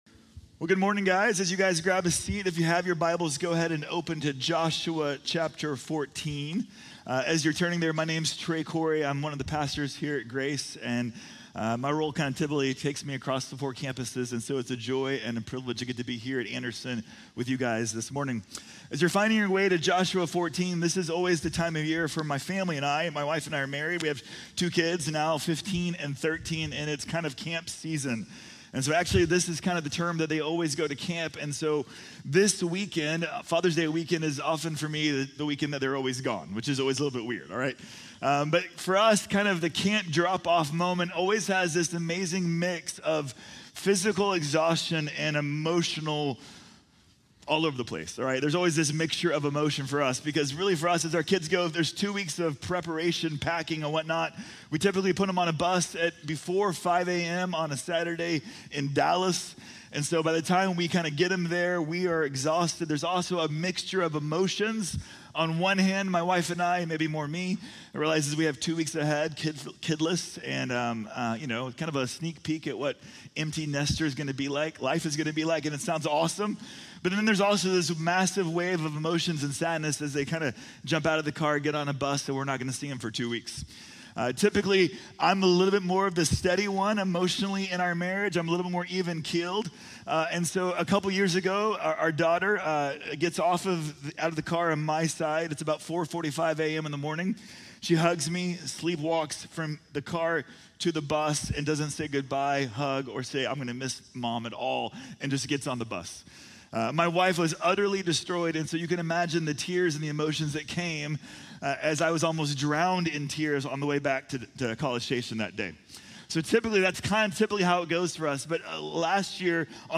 Vida de Caleb | Sermón | Iglesia Bíblica de la Gracia